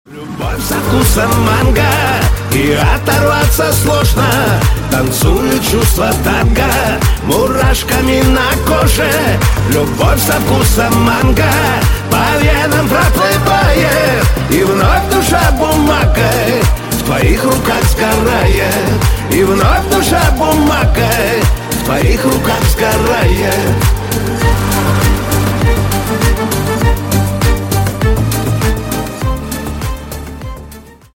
Шансон
Скачать припев песни